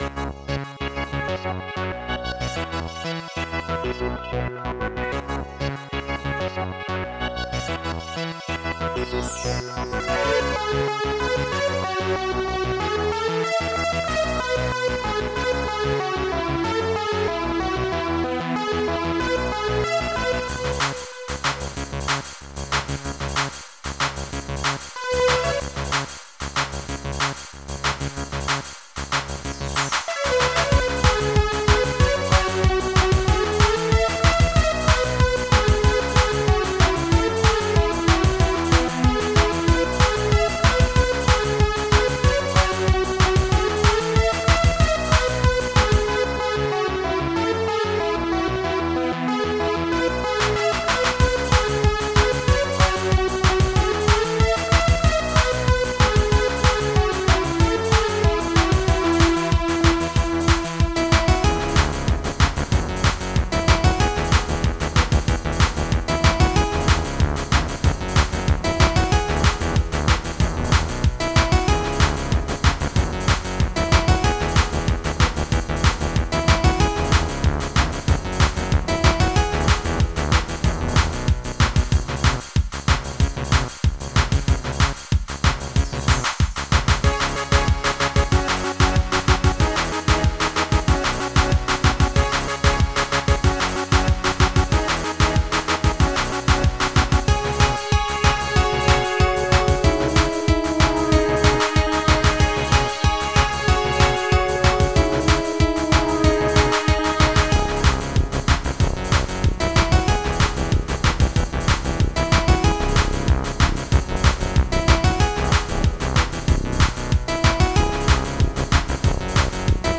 Ultra Tracker Module